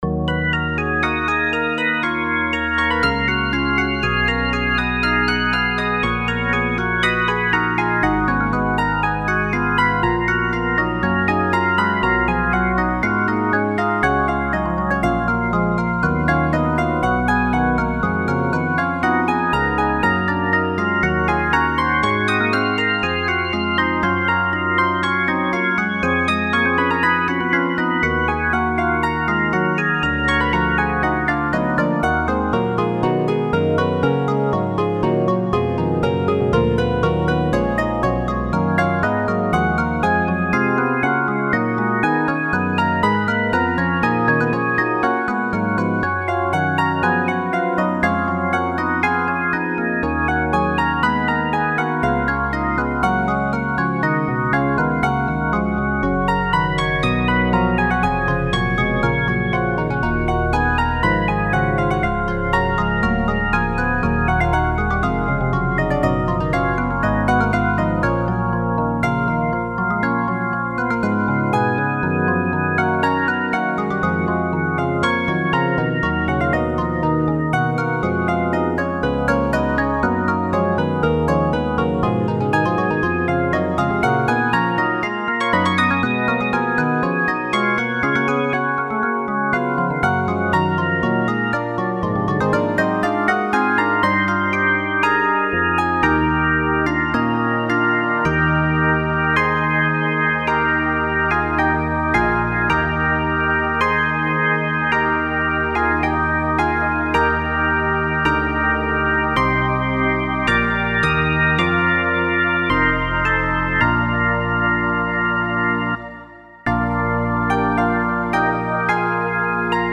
O du fröhliche (Melodie: sizilianische Volksweise) Das Weihnachtsfest lässt in der dunkelsten Zeit des Jahres ein helles Licht erstrahlen. Diese Idee des starken Kontrastes führte dazu, dem aus dem sonnigen Süden zu uns gekommenen Lied (hier im goldenen Es−Dur) ein Vorspiel in es−moll (nach der Tonartensymbolik eine „ängstliche” Tonart) mit überdies schweifender Harmonik voranzustellen.